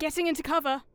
Voice Lines / Combat Dialogue
Leo taking cover.wav